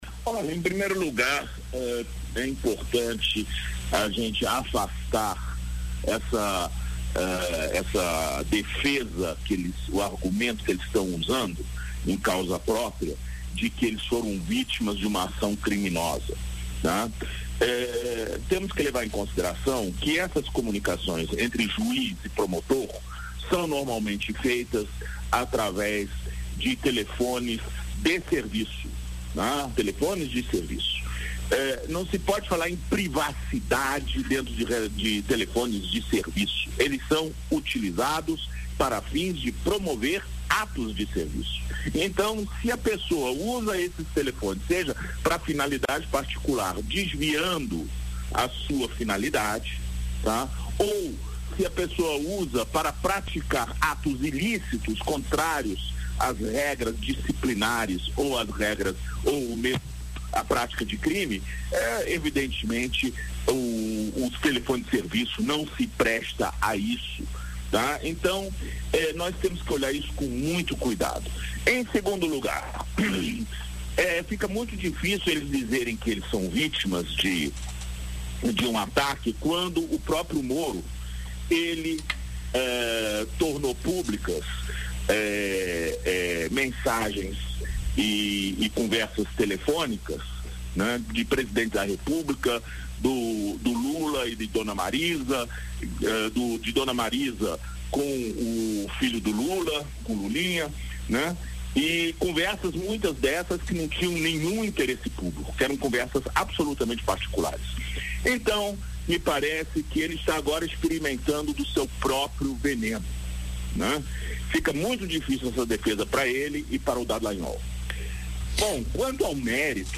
ENTREVISTA-ARAGÃO.mp3